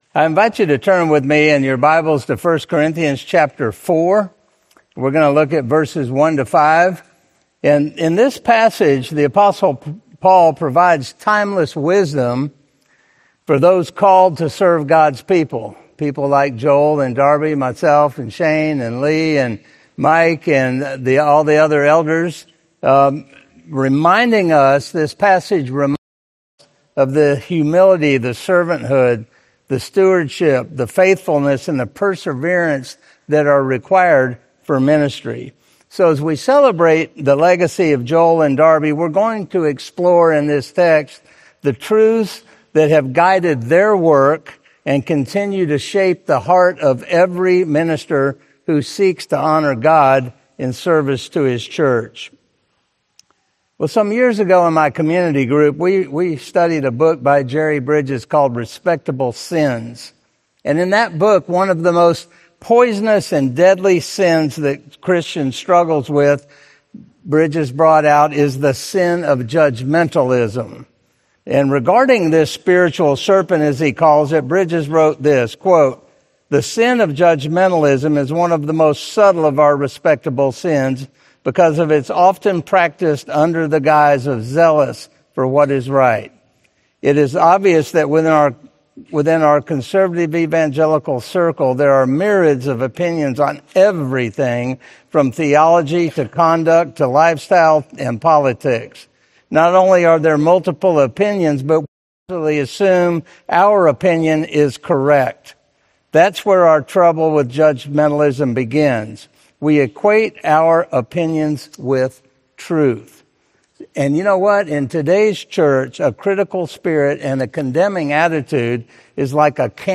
Series: Benediction Evening Service, Bible Studies